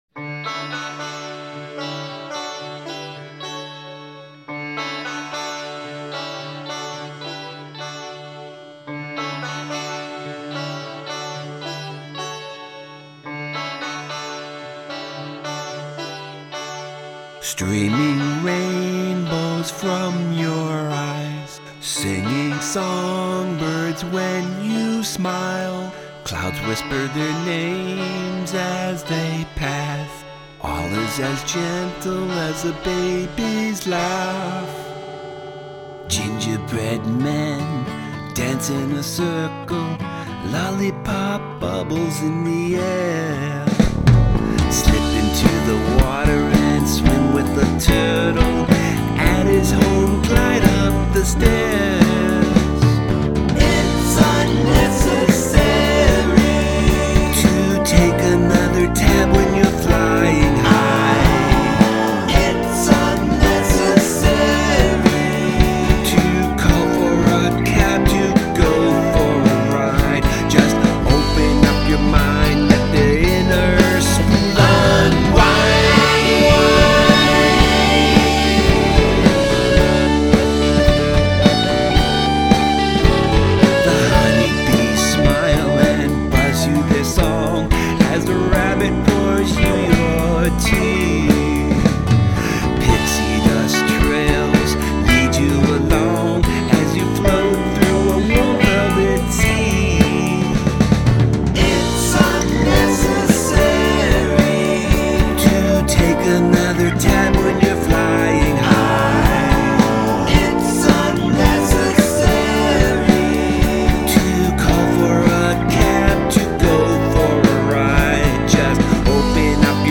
Psychedelic
Dig the hazy guitar solo.